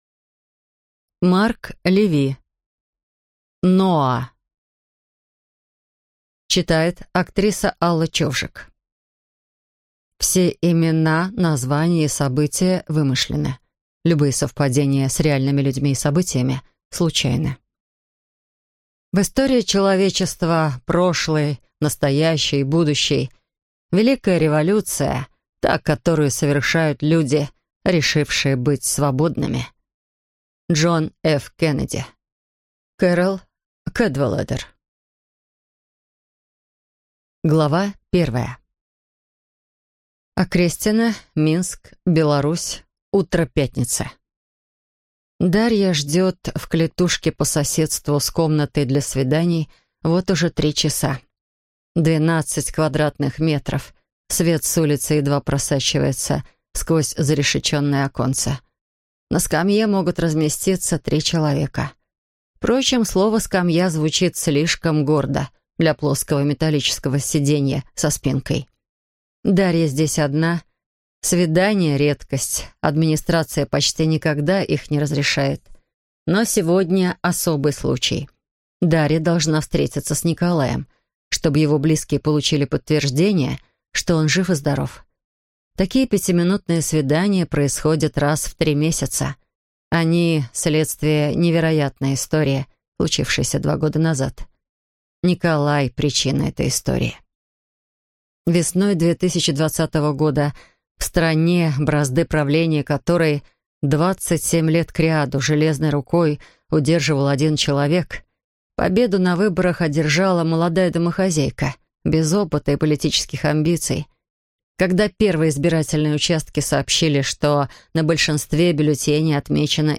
Аудиокнига Ноа | Библиотека аудиокниг
Прослушать и бесплатно скачать фрагмент аудиокниги